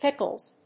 発音記号・読み方
/ˈpɪkʌlz(米国英語)/